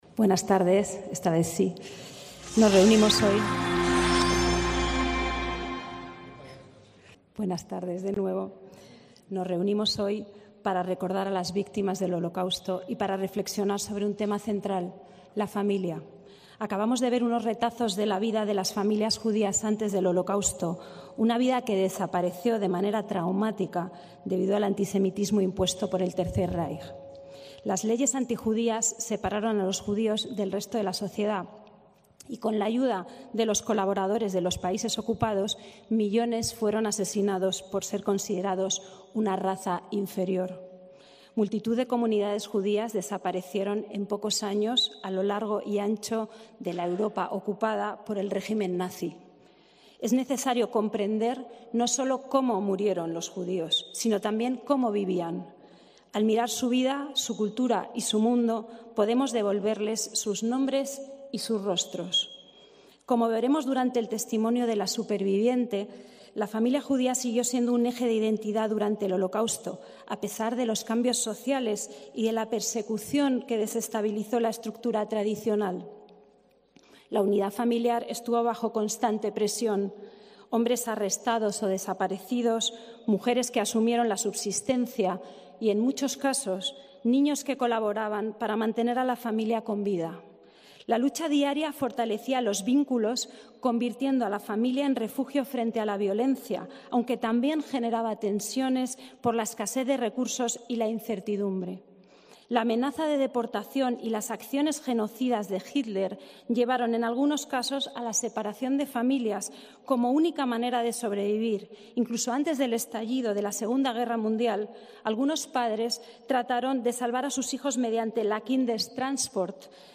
Acto por el Día Internacional en Memoria de las Víctimas del Holocausto en el Ayuntamiento de Madrid (28/1/2026)
ACTOS EN DIRECTO